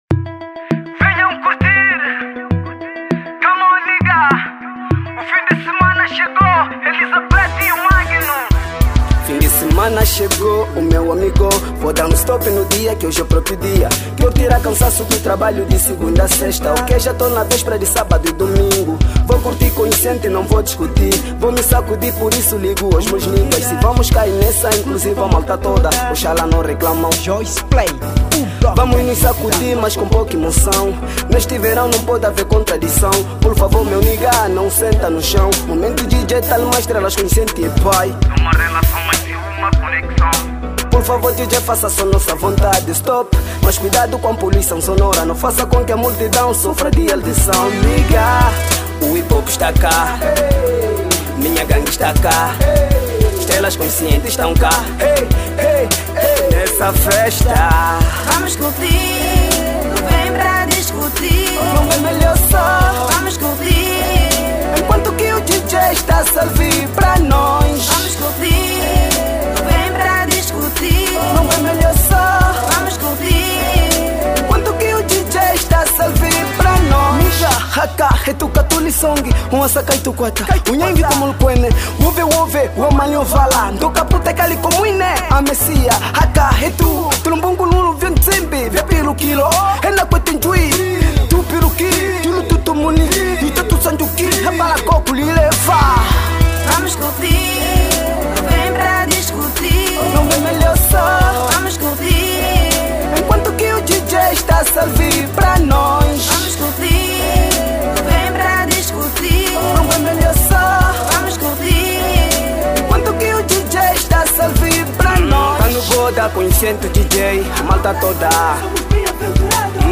Categoria: Hip-Hop/Rap